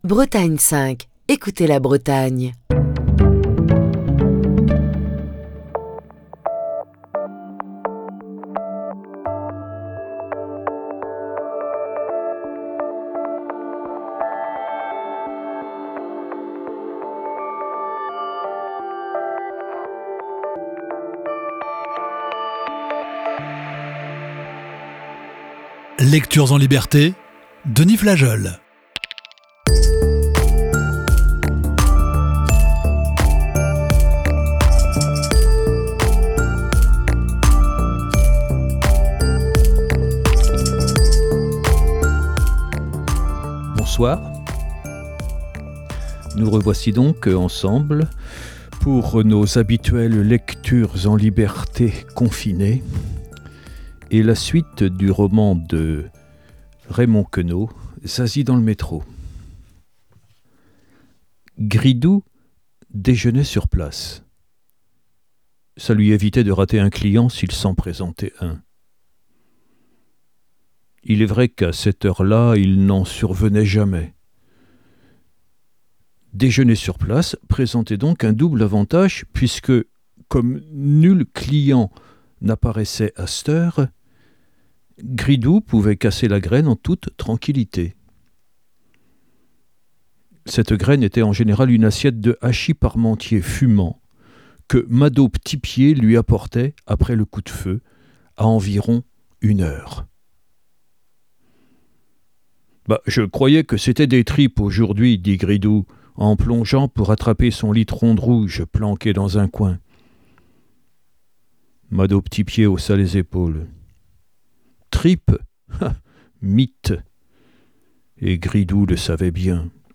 la lecture de la cinquième partie de ce récit